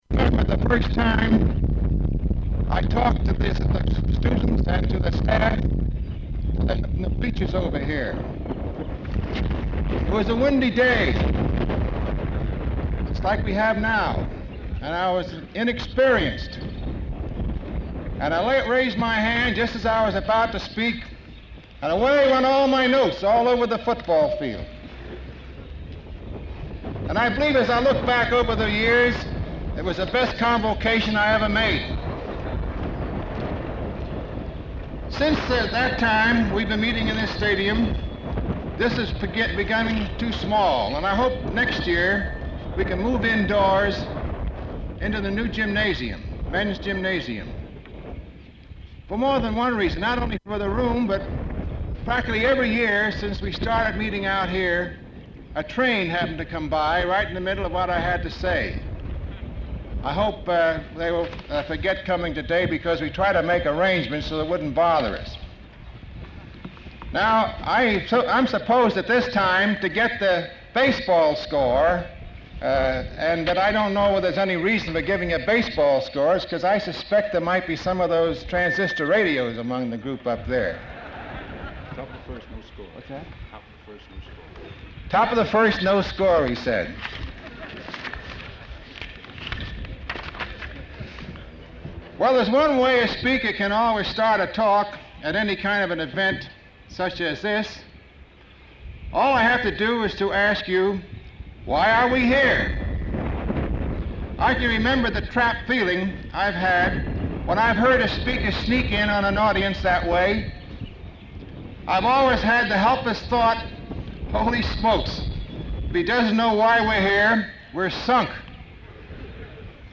Caution: Loud Wind at beginning of speech and occasionally throughout